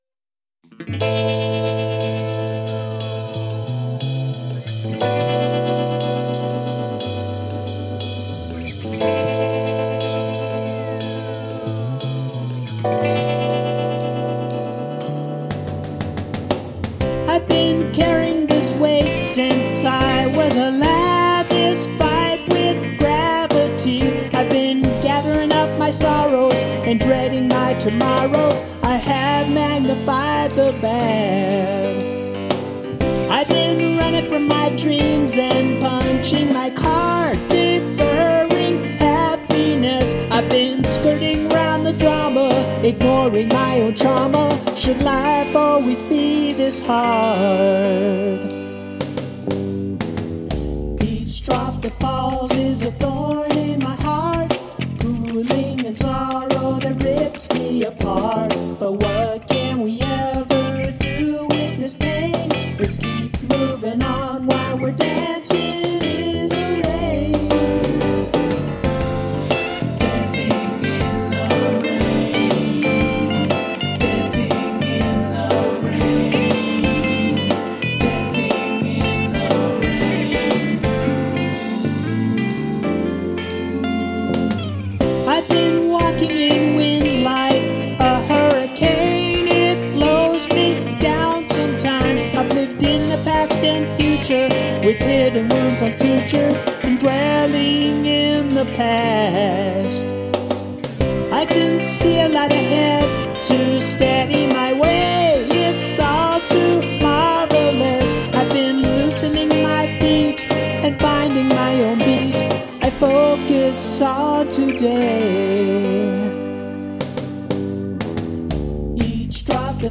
lead vocals, keyboards, drums
guitars, bass, ukulele, backing vocals, keyboards